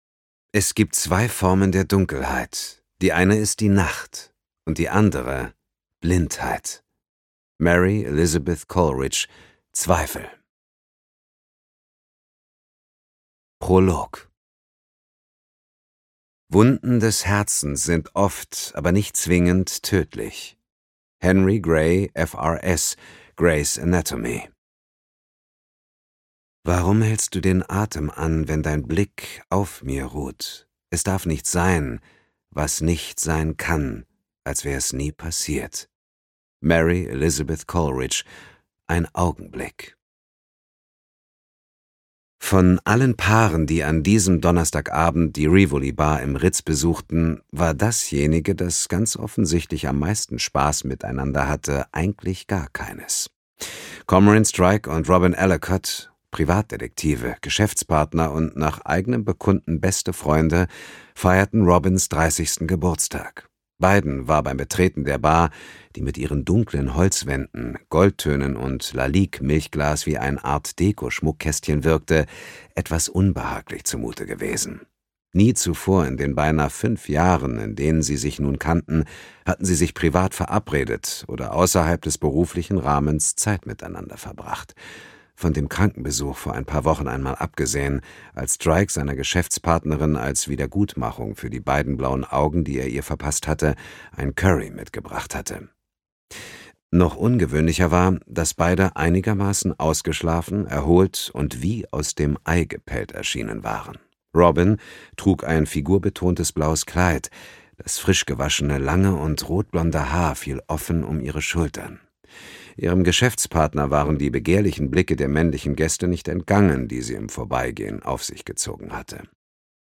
Audio knihaDas tiefschwarze Herz (DE)
Ukázka z knihy
• InterpretDietmar Wunder